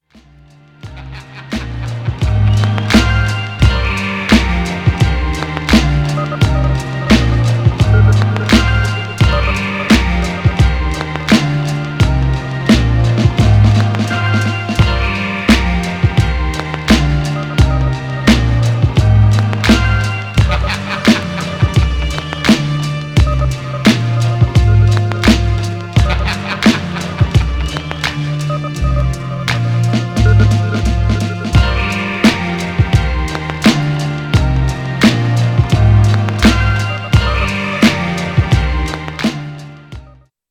Styl: Hip Hop, Lounge